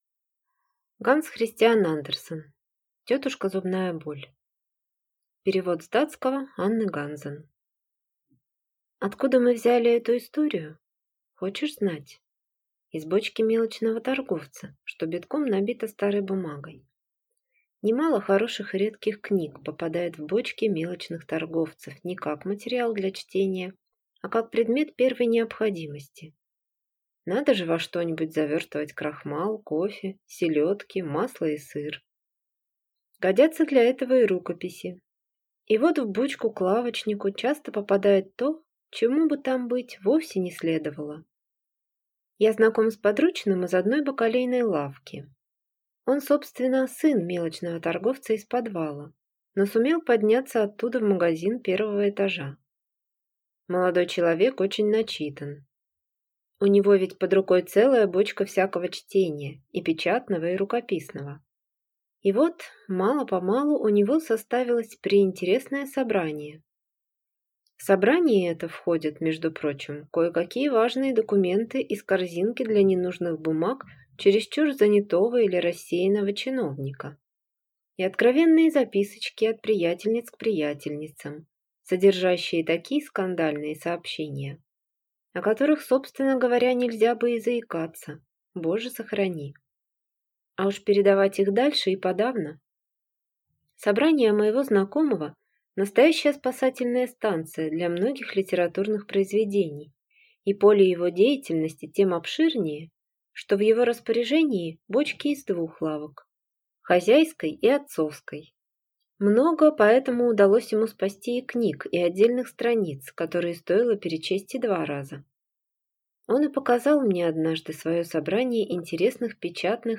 Аудиокнига Тётушка Зубная боль | Библиотека аудиокниг